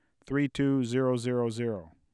5. Spectrogram and AIF tracks for speech utterance “